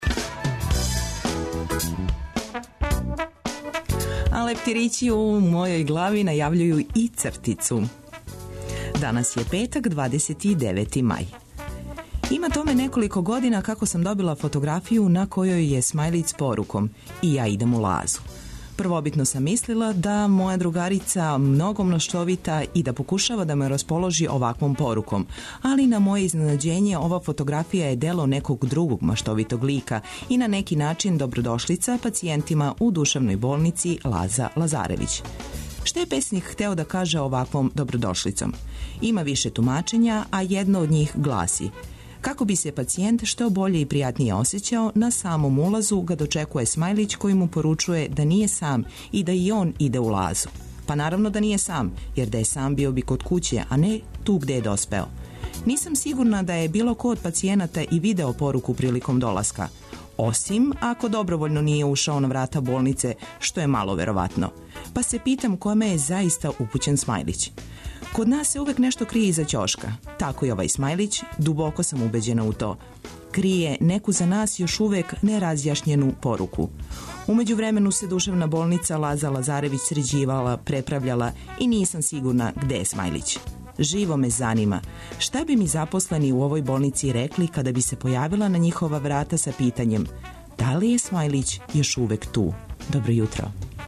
Цртица ће Вам пожелети добро јутро, Графити измамити осмех, Биоскоп 202 предложити добре филмове, сазнаћете где је добра свирка, а ту је и Хиљаду и један траг. Добра музика, сервисне, културне и спортске информације, прошараће још један Устанак од 6 до 9.